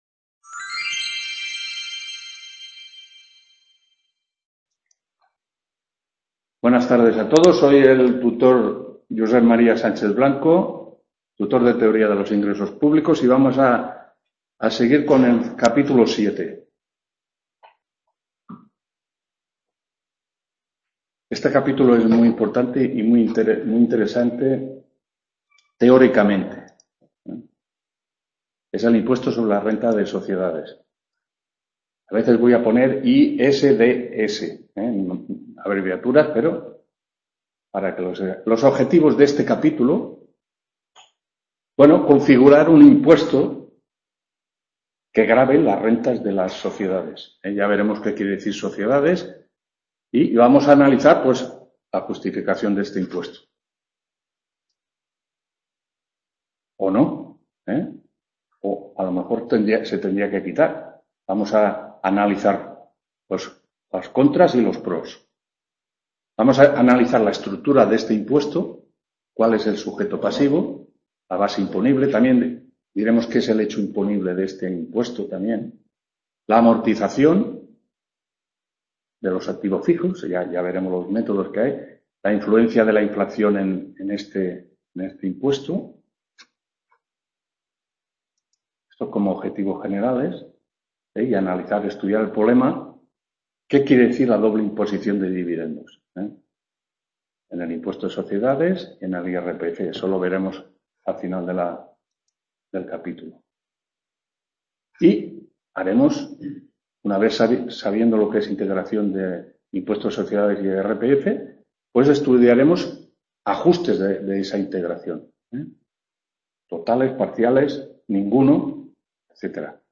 3ª TUTORÍA TEORÍA DE LOS INGRESOS PÚBLICOS 26-4-16…